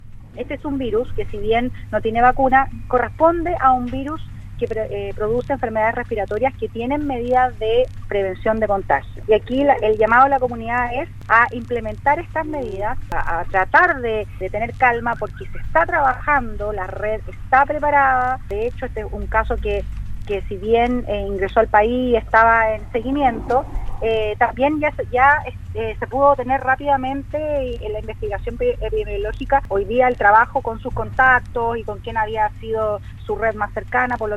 La seremi de Salud de la región de Los Lagos, en conversación con Radio Sago, se refirió al que hasta ayer era el primer caso confirmado de coronavirus en Chile, llamando principalmente a la calma ante esta situación.